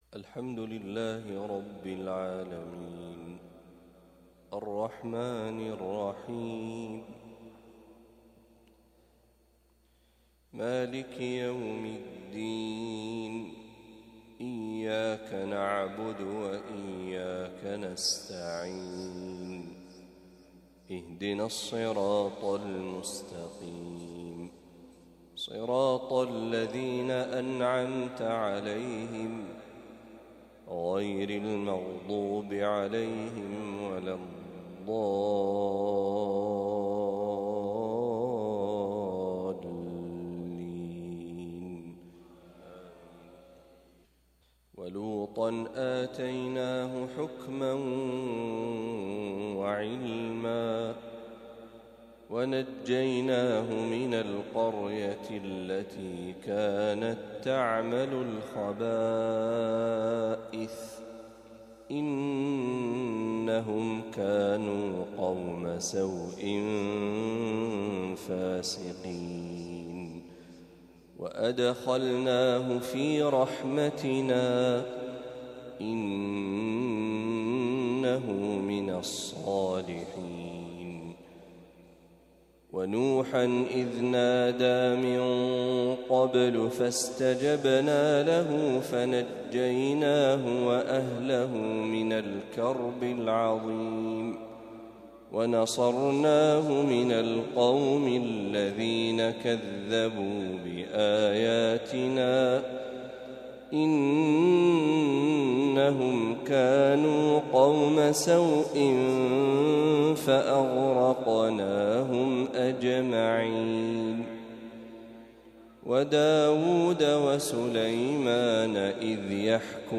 ما تيسر من سورة الأنبياء | فجر الخميس ٤ صفر ١٤٤٦هـ > 1446هـ > تلاوات الشيخ محمد برهجي > المزيد - تلاوات الحرمين